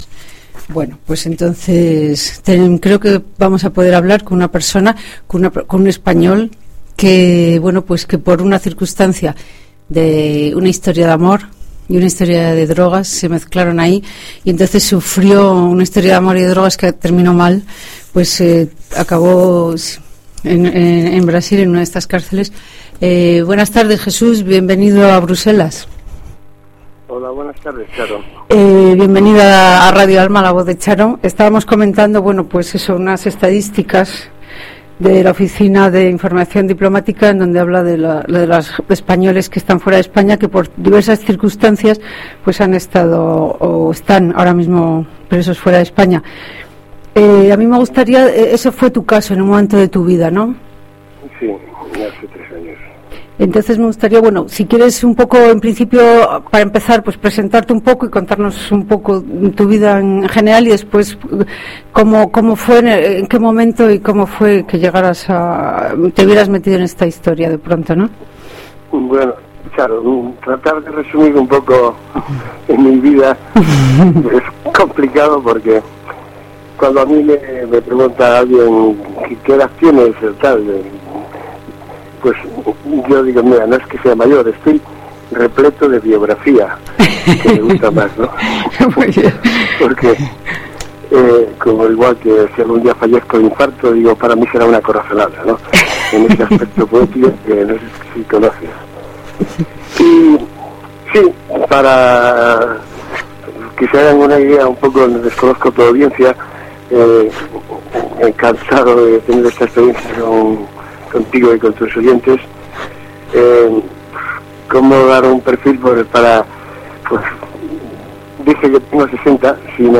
Nos relata parte de esa tremenda historia en esta entrevista, aunque necesitaríamos muchas más para poder acercarnos a esta experiencia.